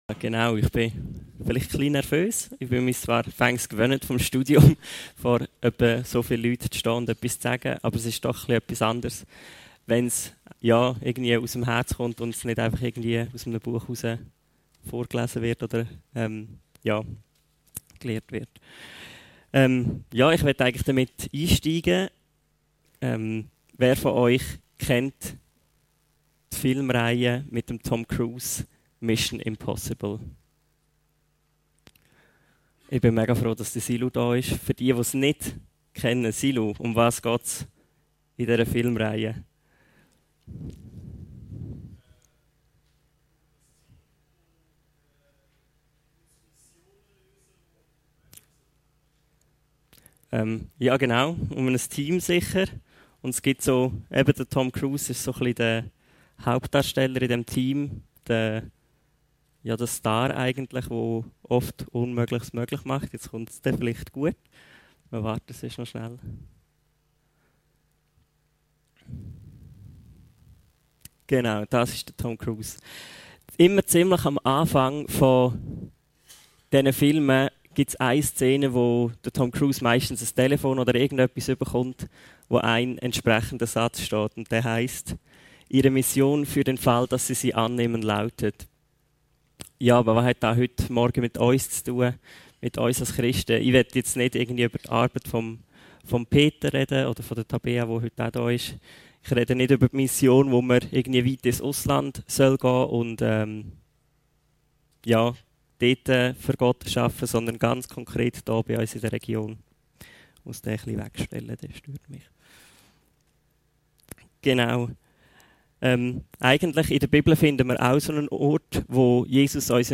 Predigten Heilsarmee Aargau Süd – MISSION IMPOSSIBLE